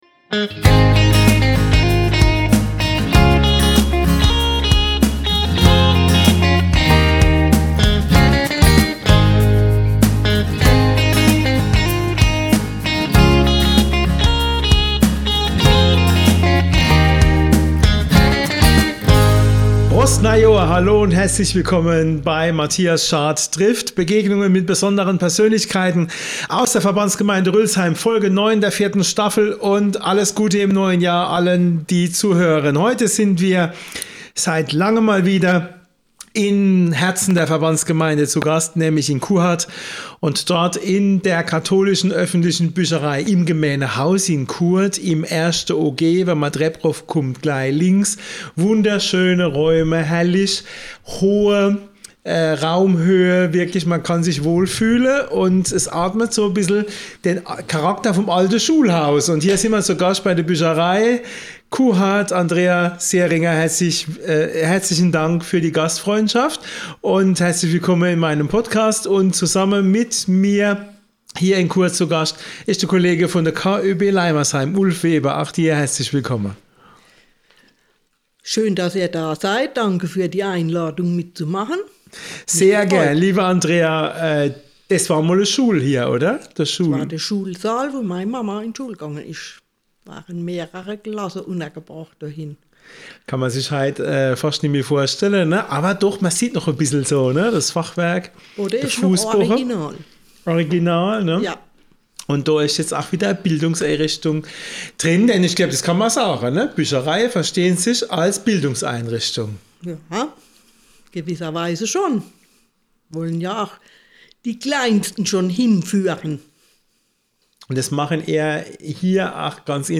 Die drei sprechen über die Aufgaben und Herausforderungen der Büchereien in der heutigen Zeit, die Veränderungen der Lesegewohnheiten über die letzten Jahre und Jahrzehnte und die Auswirkungen der Digitalisierung auf die KÖBs.